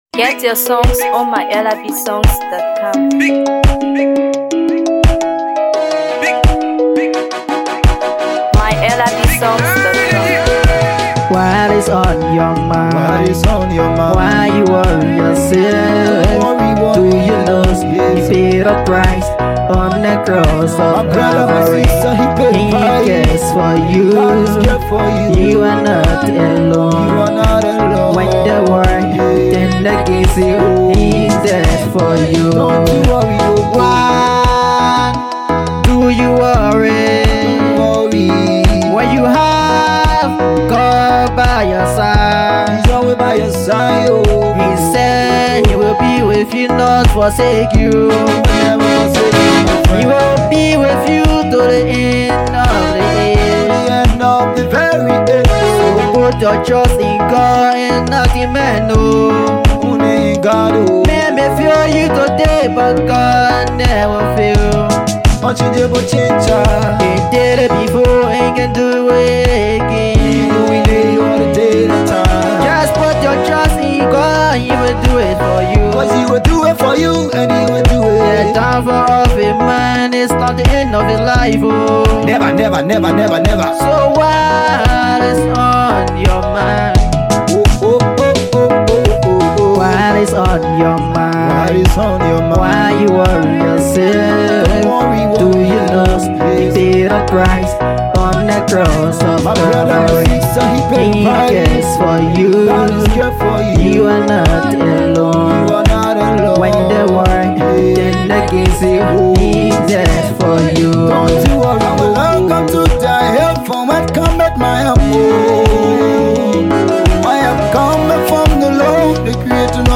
Afro PopGospel